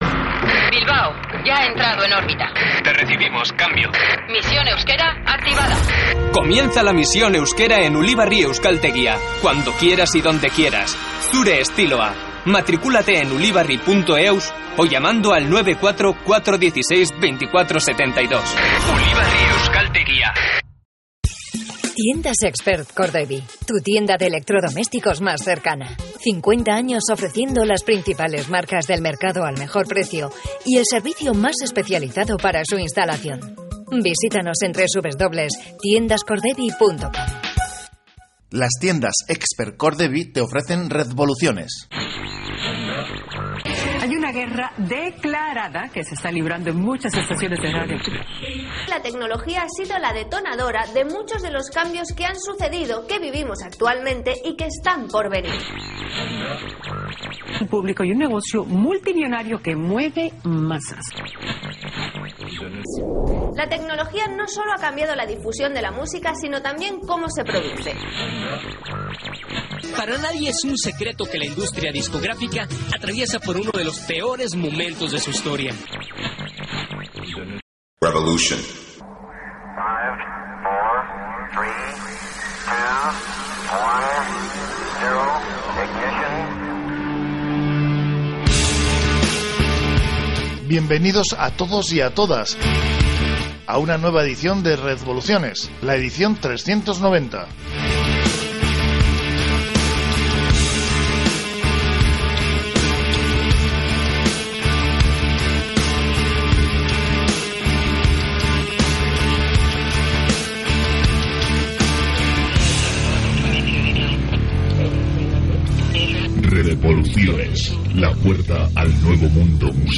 Revista de música y tecnología en el nuevo paradigma digital